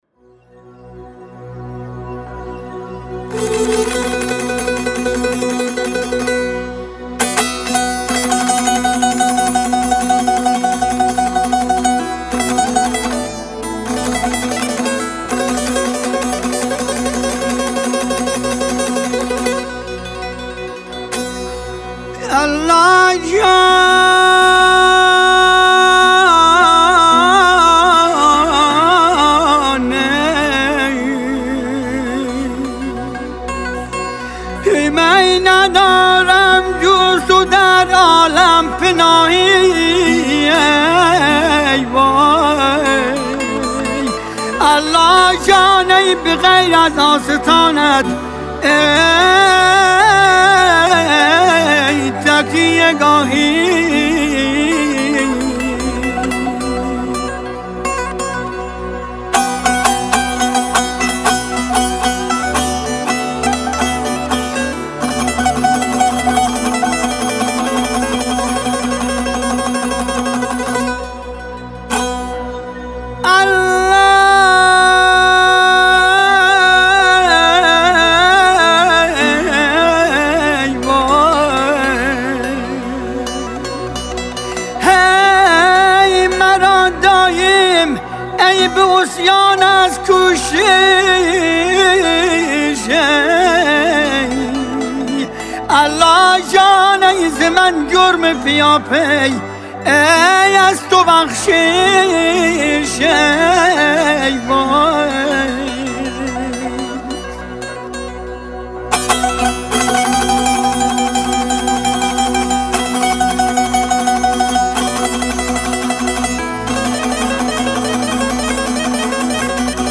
مناجات‌خوانی